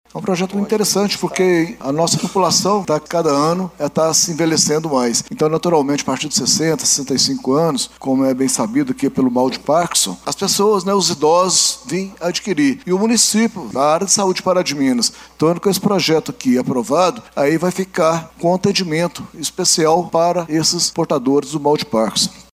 O vereador Toninho Gladstone elogiou  a proposta, ressaltando a importância de um atendimento humanizado aos portadores de parkinsonismos.